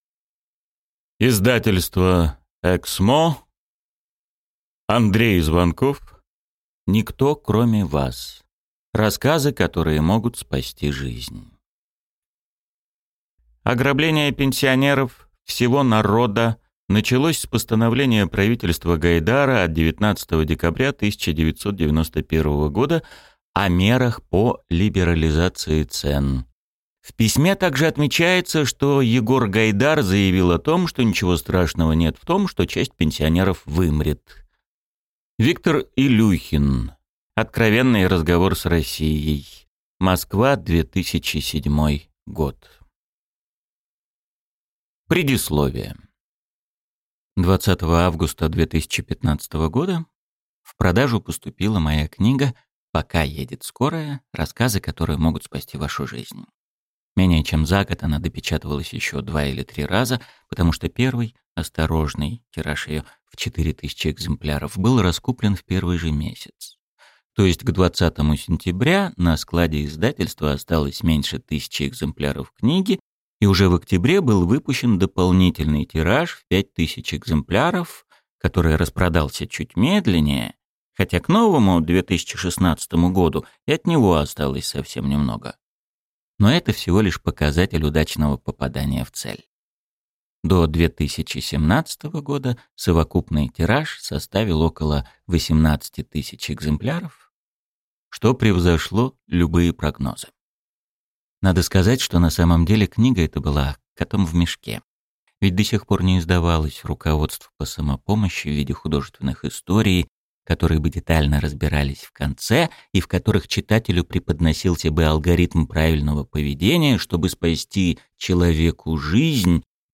Аудиокнига Никто, кроме вас. Рассказы, которые могут спасти жизнь | Библиотека аудиокниг